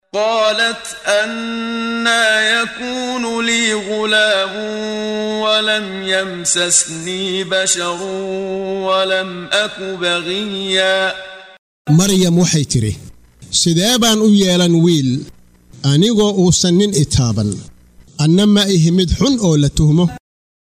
Waa Akhrin Codeed Af Soomaali ah ee Macaanida Suuradda Maryam oo u kala Qaybsan Aayado ahaan ayna la Socoto Akhrinta Qaariga Sheekh Muxammad Siddiiq Al-Manshaawi.